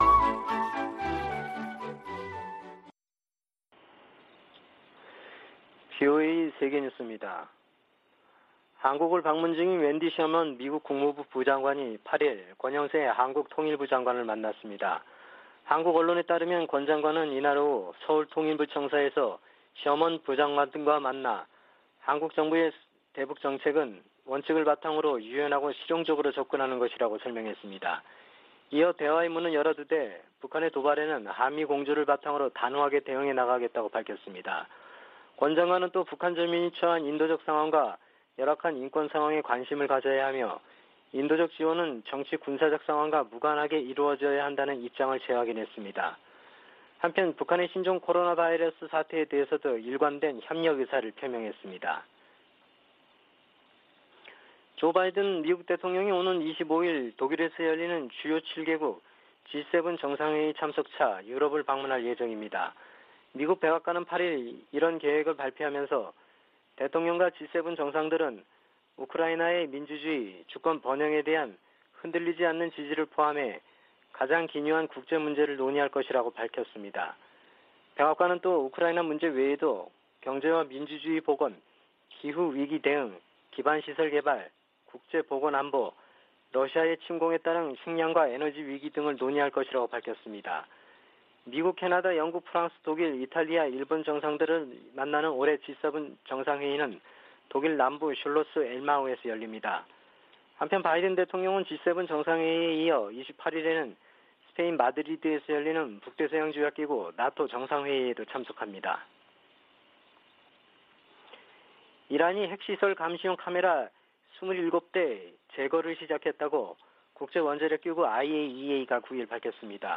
VOA 한국어 아침 뉴스 프로그램 '워싱턴 뉴스 광장' 2022년 6월 10일 방송입니다. 북한이 7차 핵실험을 강행하면 강력히 대응할 것이라는 방침을 백악관 고위당국자가 재확인했습니다. 미국의 B-1B 전략폭격기가 괌에 전진 배치됐습니다.